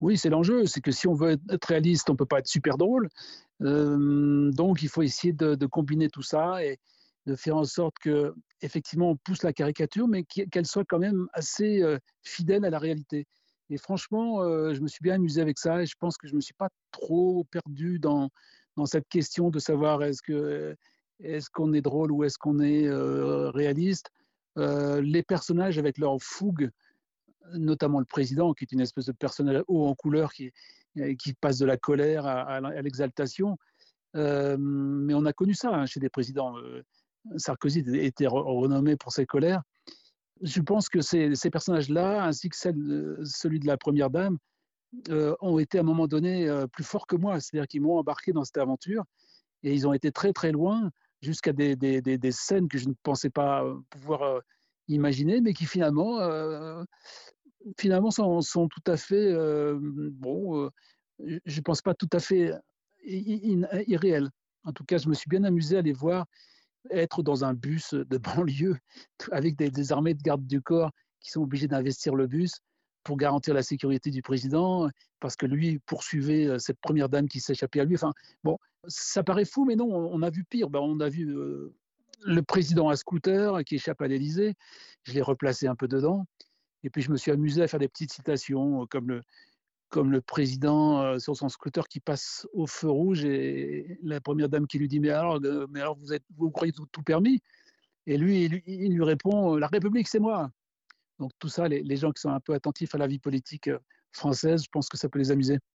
Entretien avec Didier Tronchet